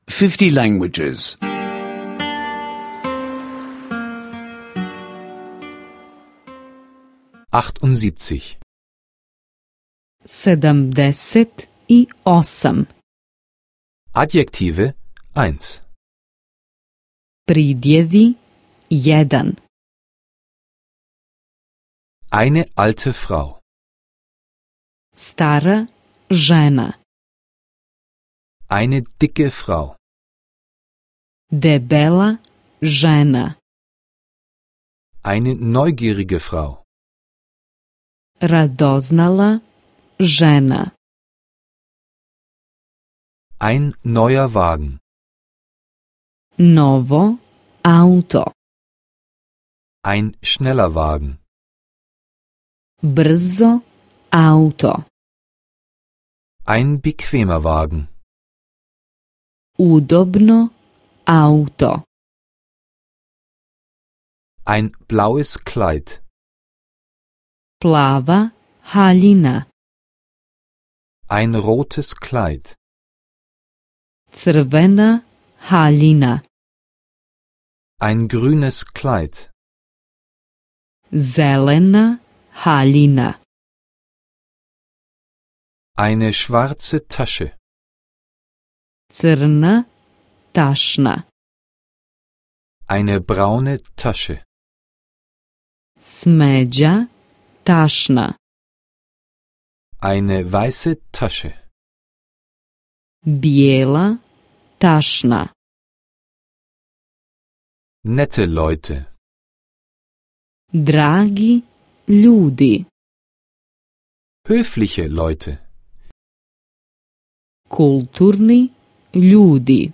Bosnisch Sprache-Audiokurs (kostenloser Download)